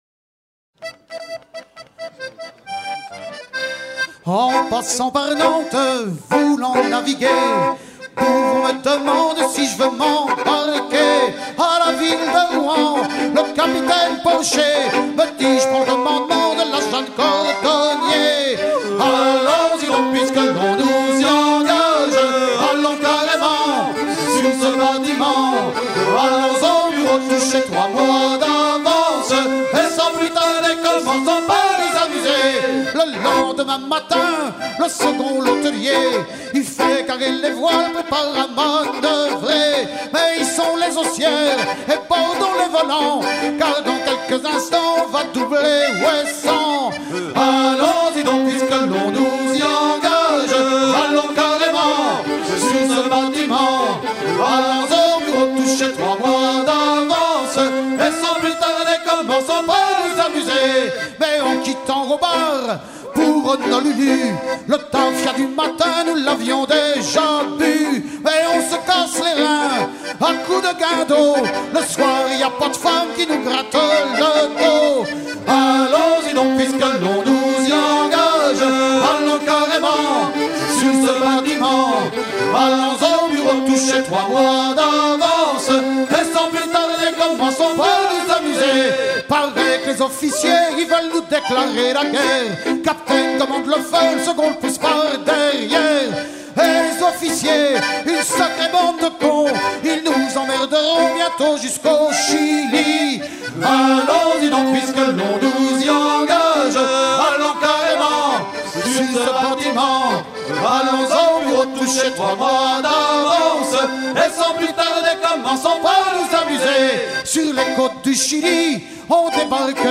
Recueilli en 1984 à Nantes
à virer au cabestan
Pièce musicale éditée